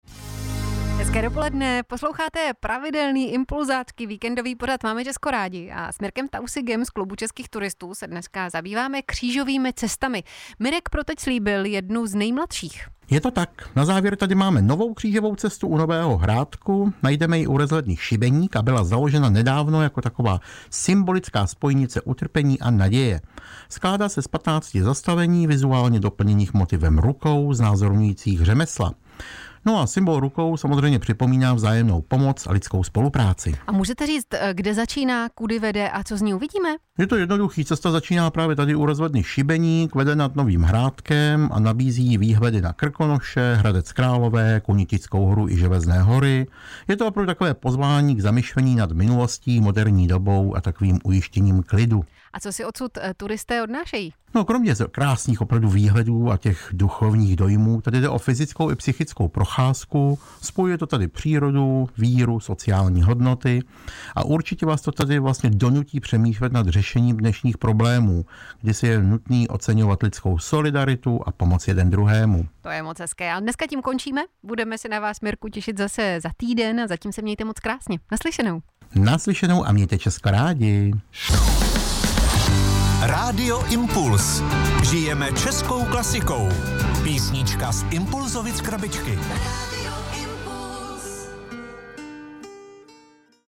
Pořad Máme česko rádi Rádia Impuls odvysílal krátkou reportáž o křížové cestě na Šibeníku.